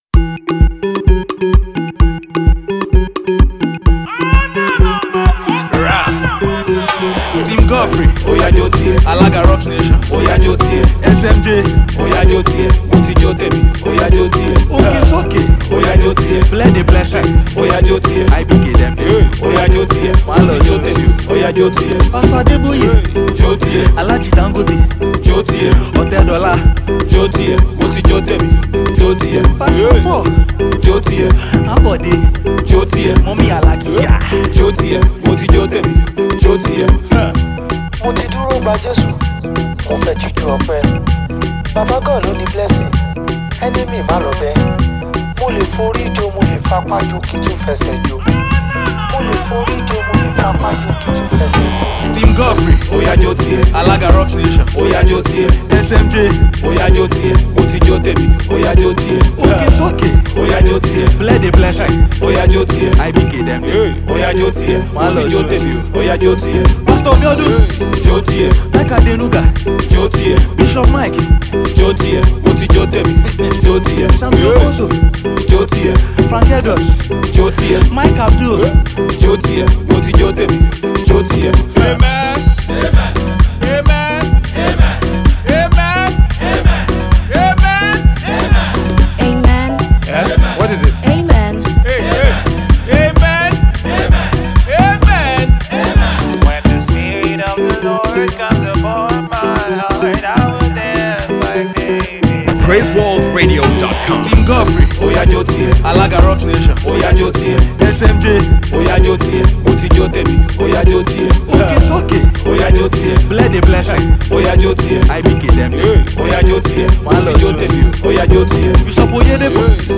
a major Heavy Tune for everyone